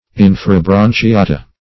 Search Result for " inferobranchiata" : The Collaborative International Dictionary of English v.0.48: Inferobranchiata \In`fe*ro*bran`chi*a"ta\, n. pl.